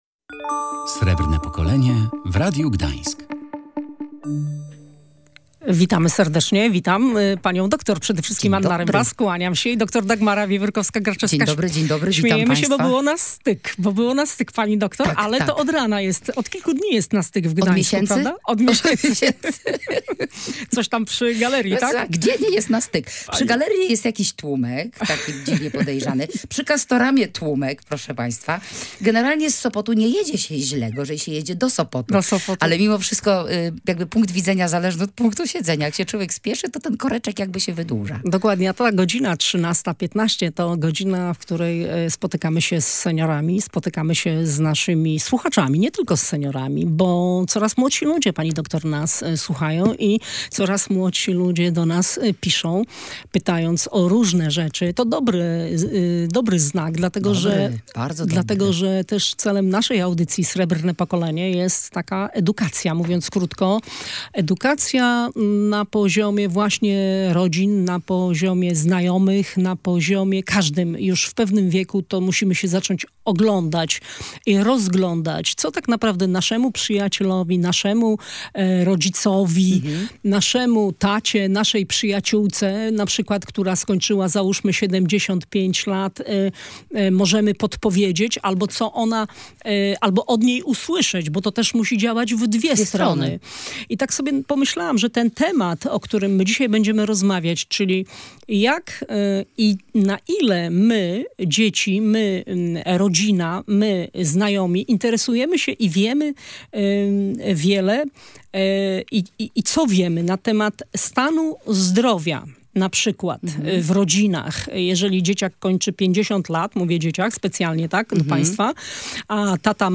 W audycji „Srebrne Pokolenie” rozmawialiśmy o relacjach pomiędzy seniorami a ich dziećmi lub najbliższą rodziną. Czy znamy nazwy chorób i leków, które przyjmują nasi starsi rodzice lub przyjaciele?